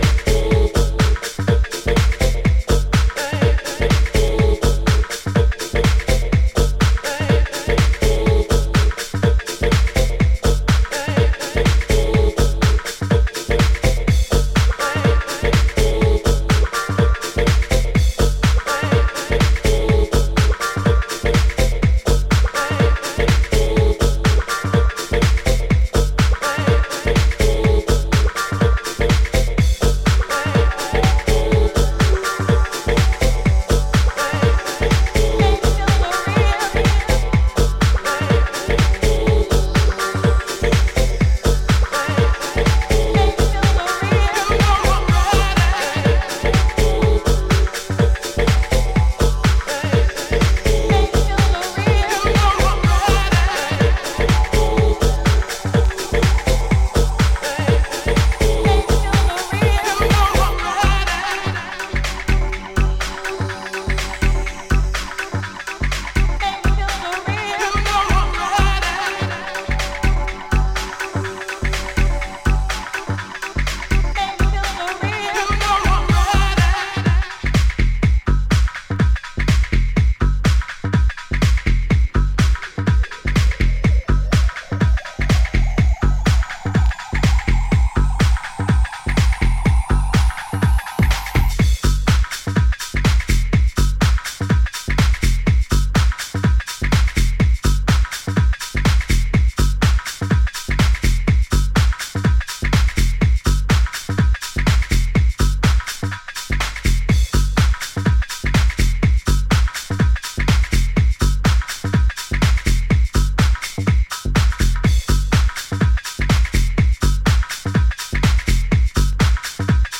ルーピーなディスコサンプルとベースラインが主導する